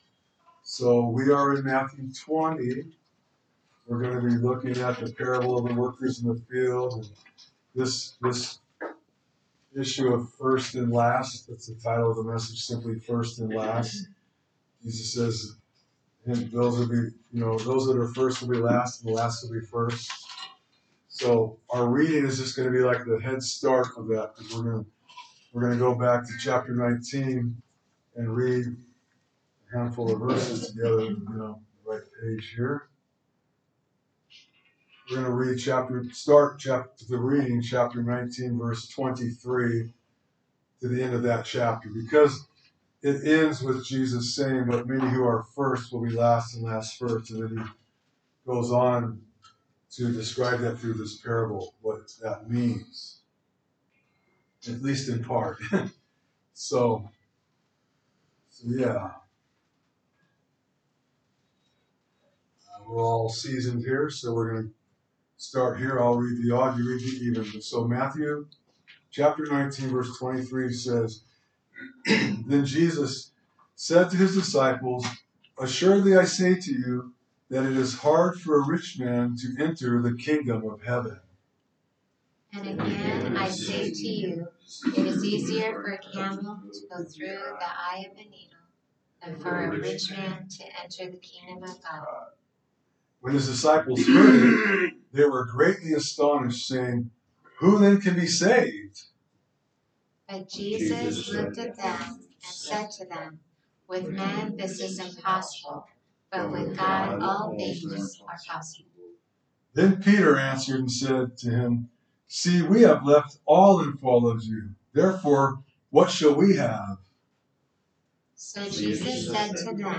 A message from the series "Matthew."